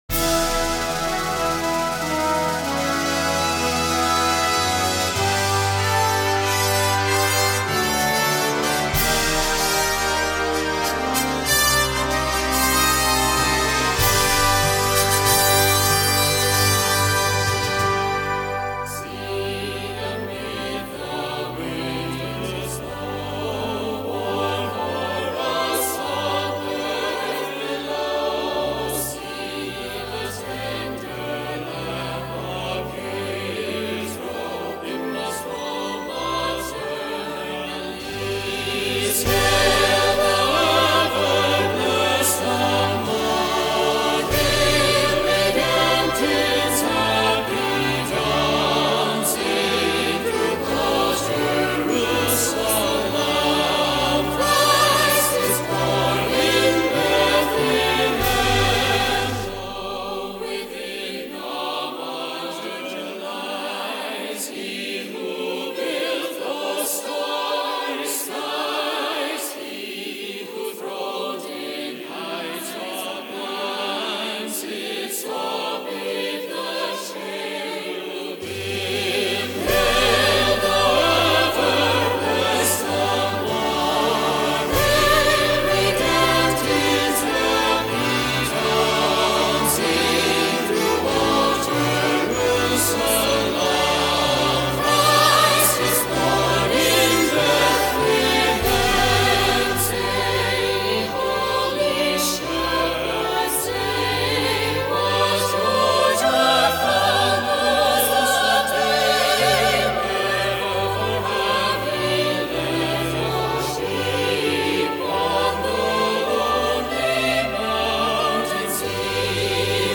The choir parts are optional.
Flutes 1-2
Bb Clarinets 1-2-3
Eb Alto Saxophones 1-2
Horns in F 1-2
Bb Trumpets 1-2-3
Tenor Trombones 1-2
Euphonium
Tuba
Timpani
Glockenspiel
Tubular Bells
Choir (SATB)
Concert Wind Band